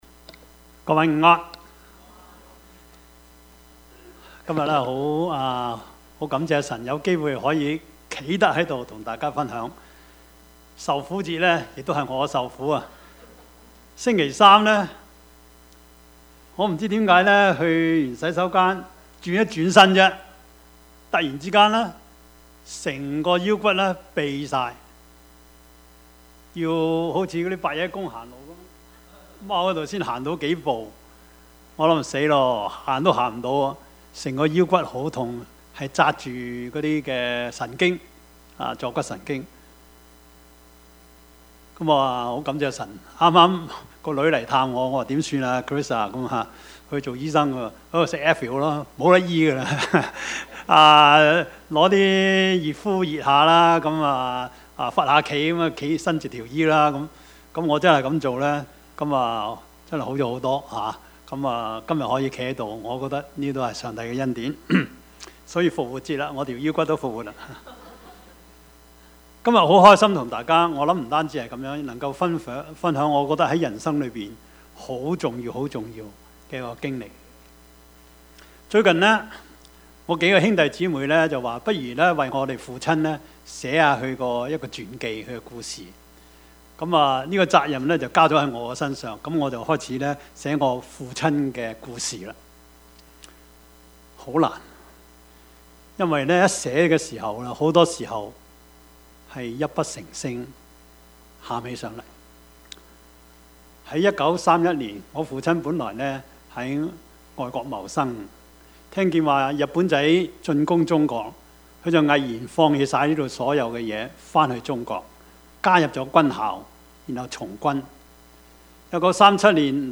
Service Type: 主日崇拜
Topics: 主日證道 « 教會是我家 教學相長 »